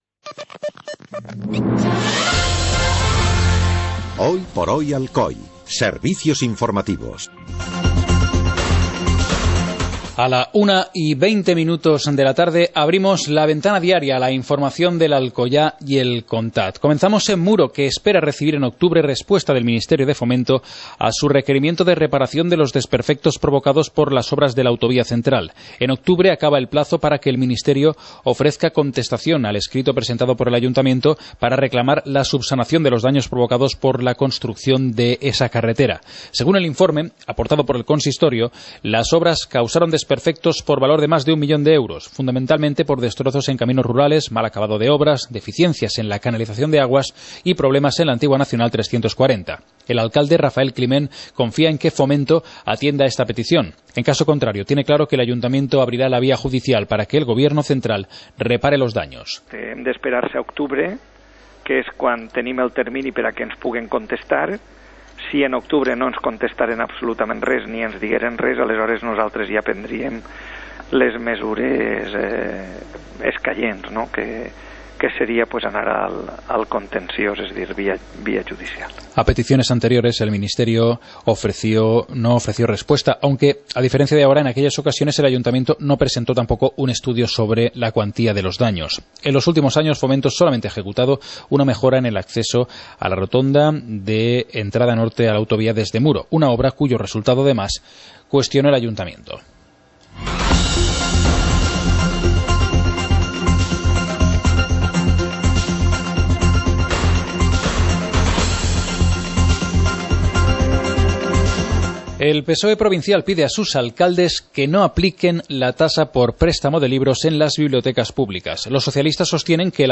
Informativo comarcal - jueves, 21 de agosto de 2014